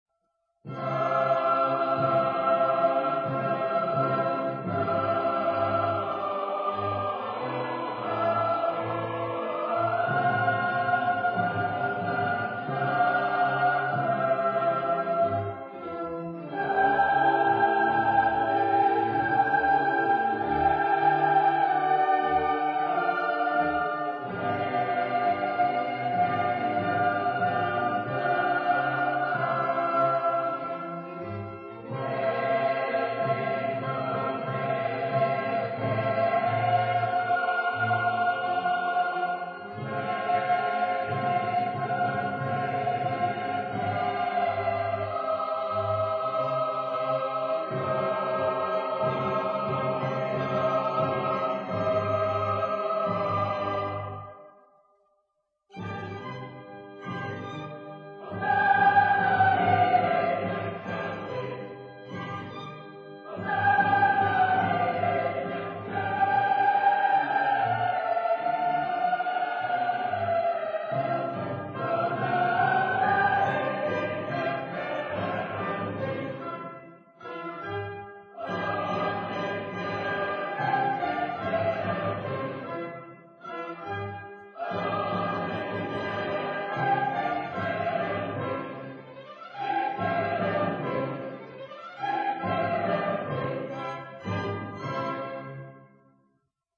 Historic Recordings
Recorded at Holy Family Catholic Church, Fort Worth, Texas
organ